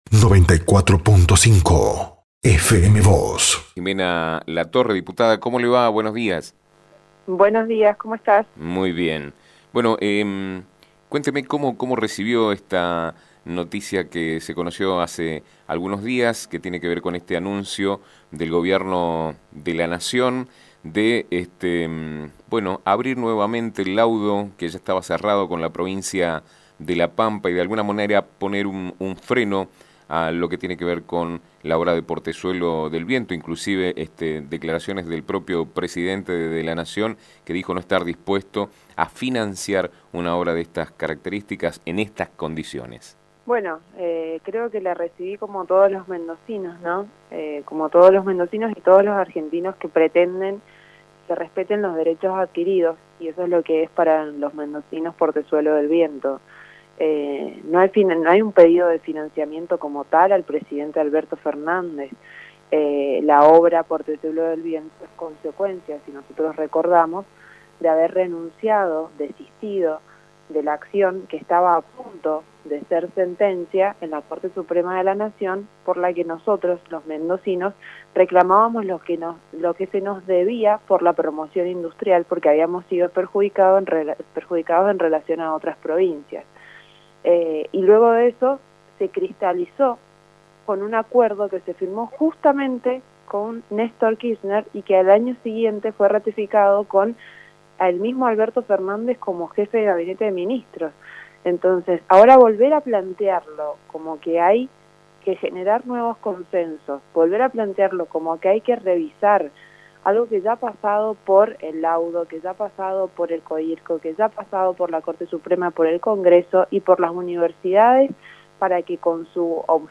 En diálogo con FM Vos (94.5) y Diario San Rafael, la diputada nacional Jimena Latorre (UCR) se expresó en torno a la polémica por Portezuelo del Viento tras los dichos del presidente Alberto Fernández vinculados a la posible reapertura del laudo con la provincia de La Pampa, y se mostró muy crítica respecto al accionar de la oposición mendocina.